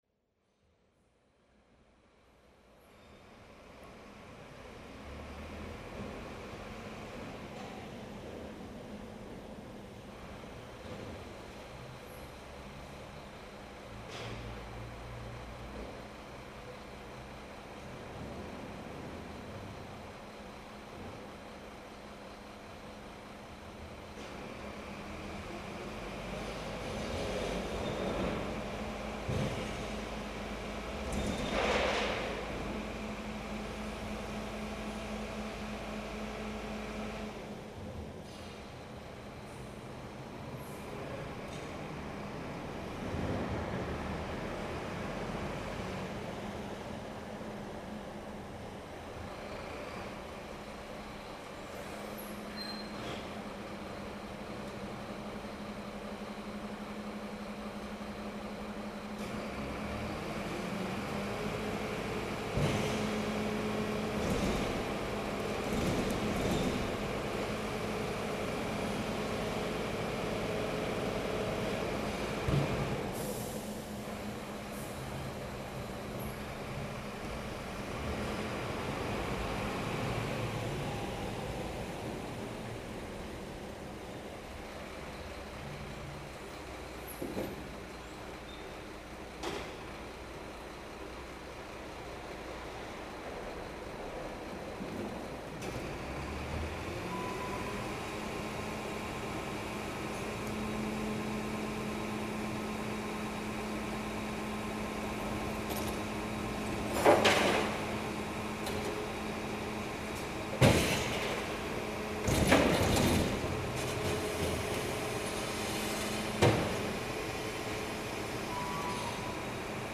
The dust van on Újezd | Zvuky Prahy / Sounds of Prague
field recordings, sound art, radio, sound walks
Popeláři na Újezdě
Tagy: exteriér
Je to někdy trochu brutální prolínačka mezi snem a probuzením - zvlášť když někdo nevyhodí sklo do určených kontejnerů. Dvakrát týdně se brzo ráno hladový brouk pomalu šine ulicí a nekompromisně vítá lidi do nového dne.